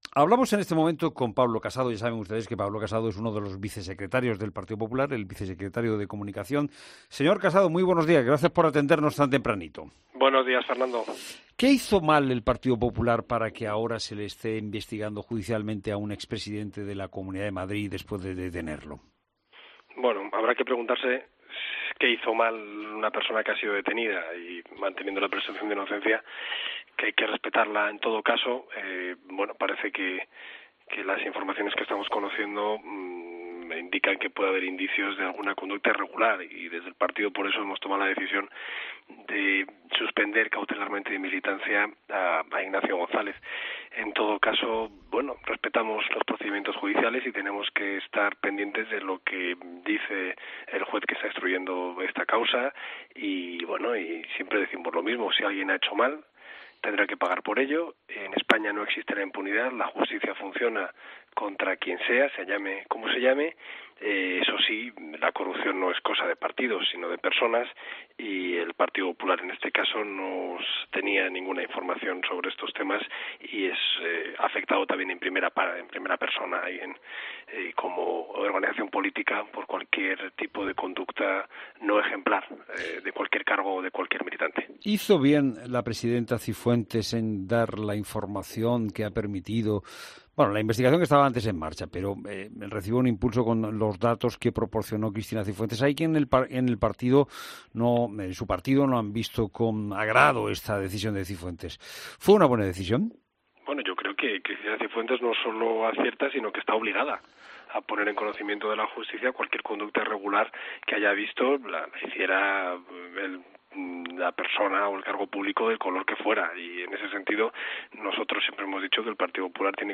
Pablo Casado, vicesecretario de comunicación del PP